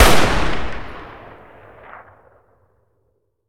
Datei:Wpn huntingrifle fire 2d 03.ogg